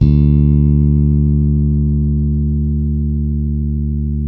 -MM JAZZ D 3.wav